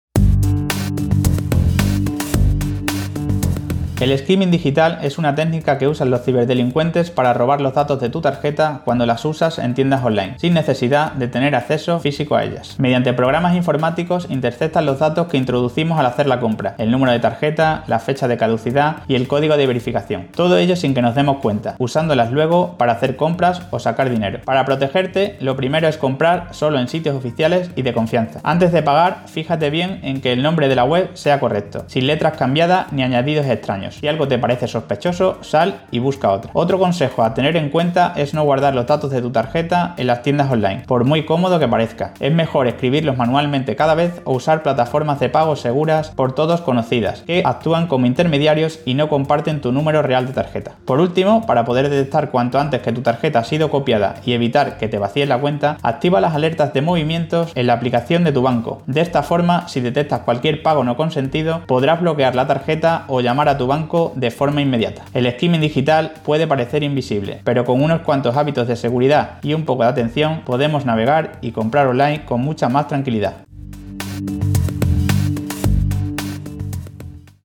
La Policía Nacional ha activado una campaña preventiva contra ciberfraudes mediante micropodcast semanales narrados por agentes de su Unidad Central de Ciberdelincuencia (UCC).